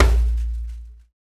Index of /90_sSampleCDs/Roland LCDP14 Africa VOL-2/PRC_Af.Hand Drm2/PRC_Djembe Drums
PRC HAND D05.wav